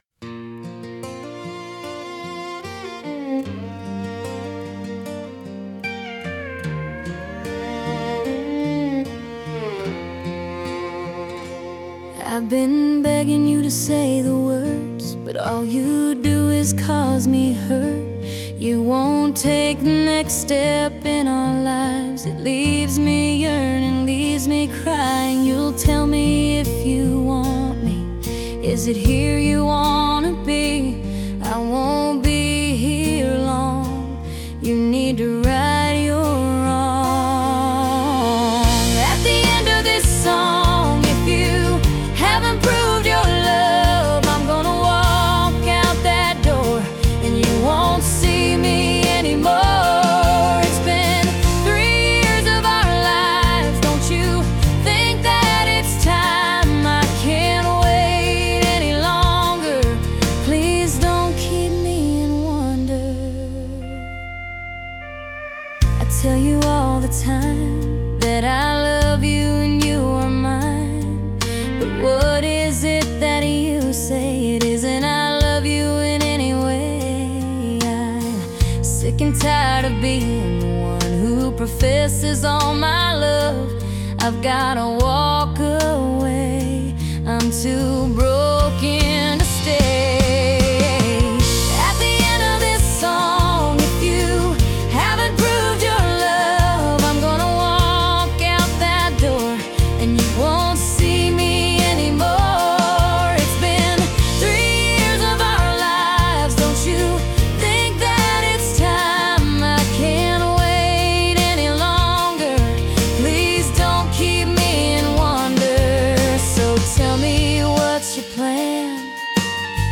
A country song about a woman giving her lover an ultimatum.
Category: Country